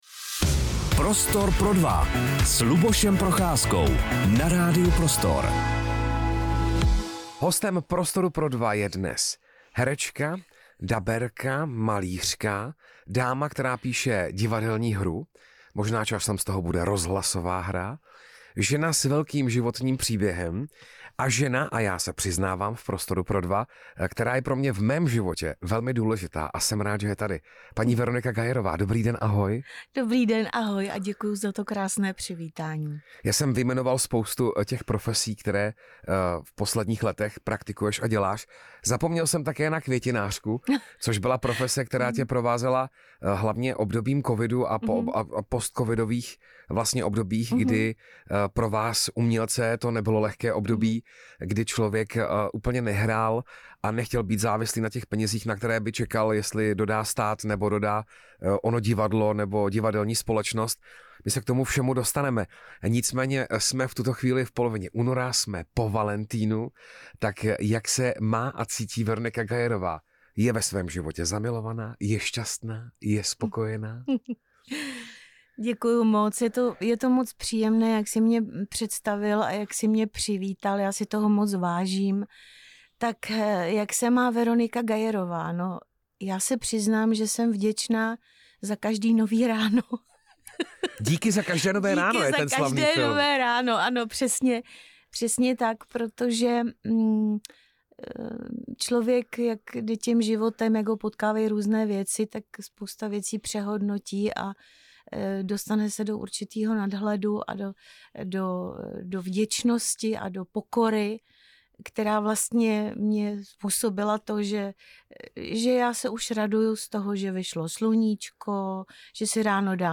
V posledních letech se věnuje nejen divadlu, ale také malbě a psaní vlastní divadelní hry. V Prostoru pro dva hovořila o životních výzvách, vděčnosti, tvorbě i o tom, proč se rozhodla obejít bez sociálních sítí.